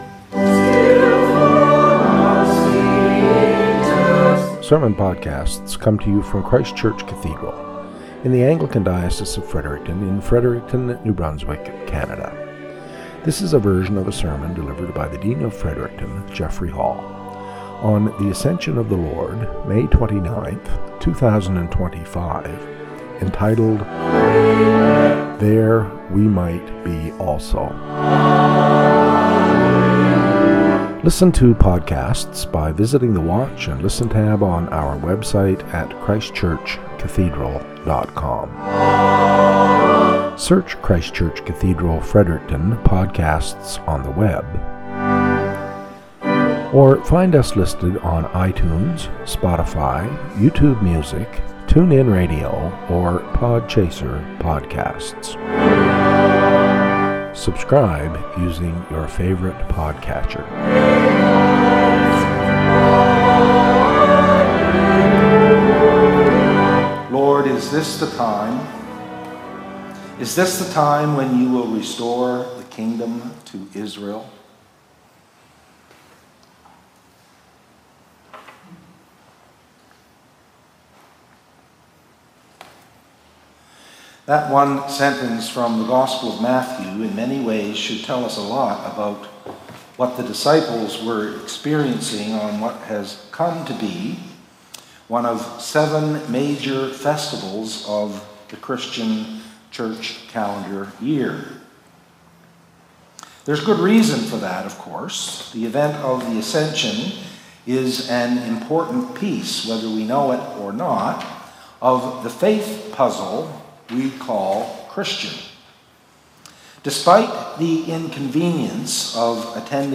SERMON - "There We Might Be Also"